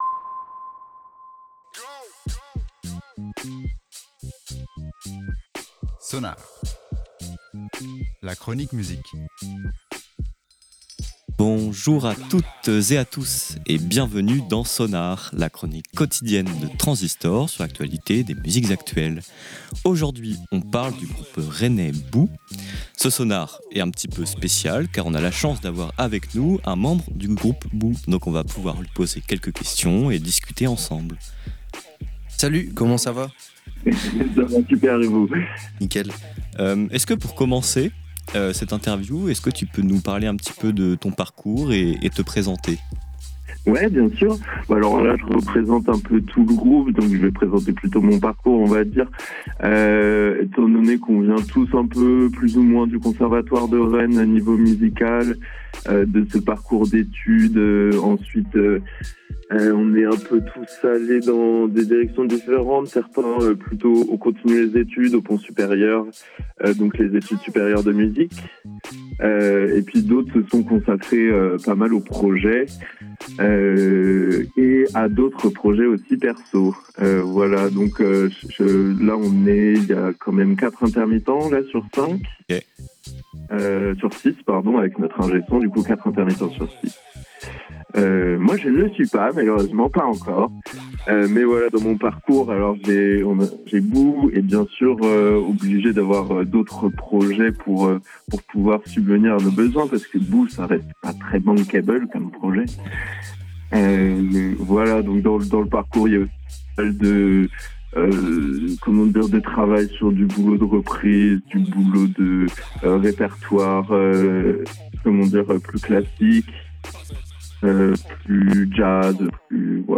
Sonar Interview sur le groupe rennais bou!